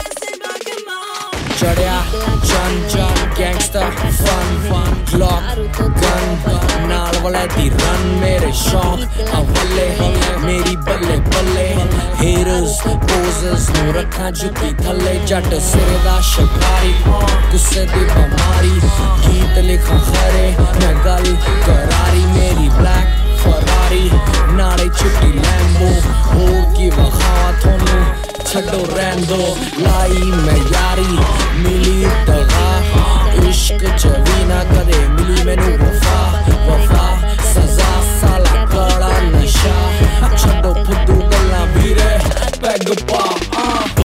remix ringtone